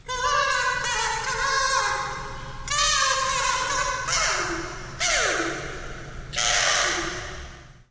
Tag: 即兴 免费 卡祖笛 样品 声音